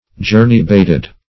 Meaning of journey-bated. journey-bated synonyms, pronunciation, spelling and more from Free Dictionary.
Journey-bated \Jour"ney-bat`ed\